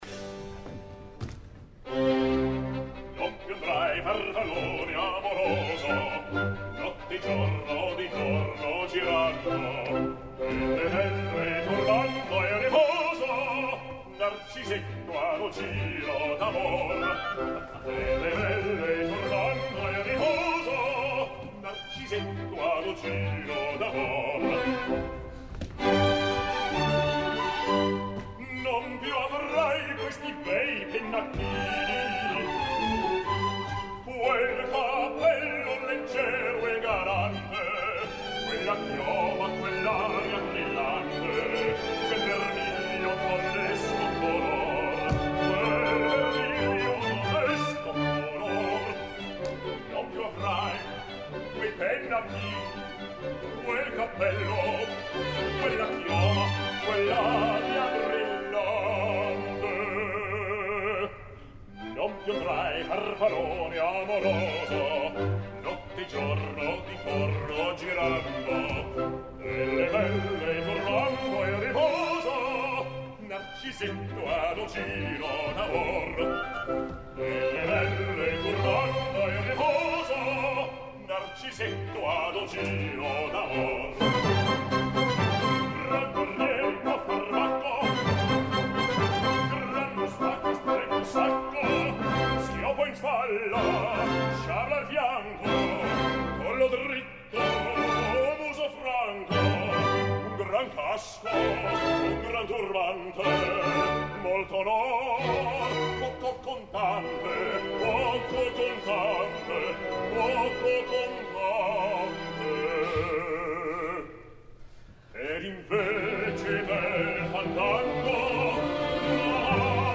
Lyric Opera of Chicago, 28 de febrer de 2010
La veu perd projecció en la zona greu, per la qual cosa el situaria avui per avui, més en la tessitura baritonal que no pas en la de baix i potser el rol de Comte s’adequaria millor a les seves característiques vocals.